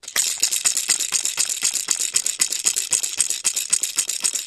Baby Toy Rattle, Shaking